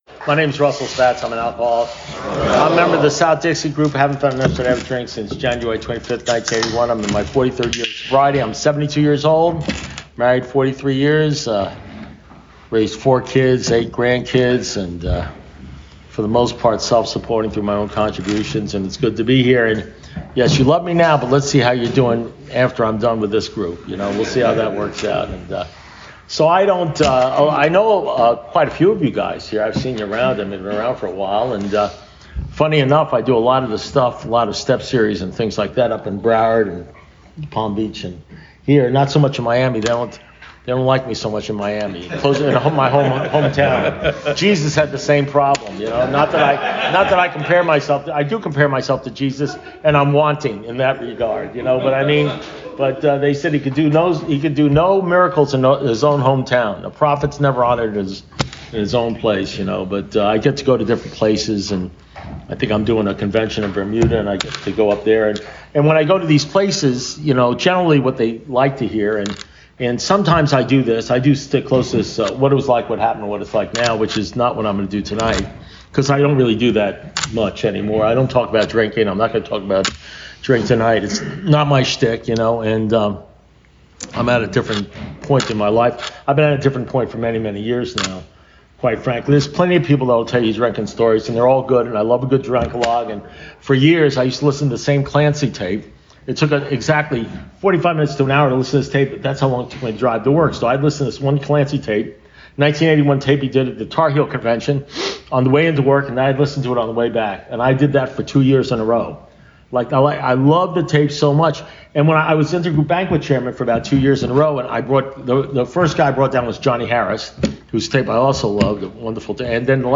AA Speaker Recordings AA Step Series Recordings Book Study